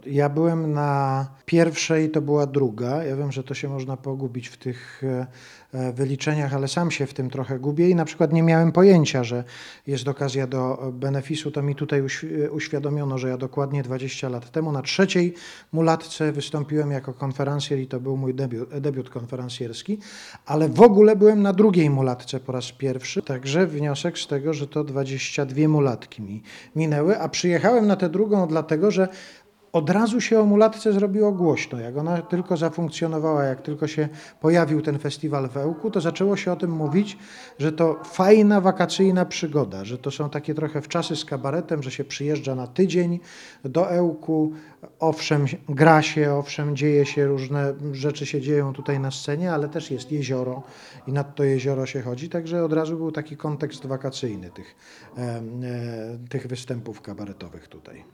W rozmowie z reporterem Radia 5 Artur Andrus wspomniał, że to właśnie w Ełku 20 lat temu zaczęła się jego przygoda z konferansjerką.